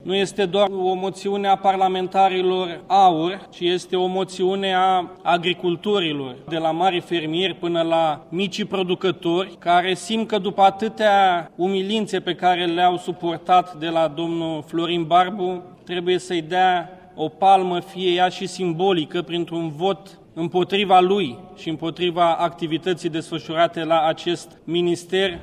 Iniţiatorii demersului susţin că, pe parcursul mandatului acestuia, contribuţia agriculturii la Produsul Intern Brut s-a prăbuşit, deficitul comercial din sectorul agricol a crescut alarmant, iar fermierii români ar fi fost abandonaţi. Liderul deputatilor AUR Mihai Enache: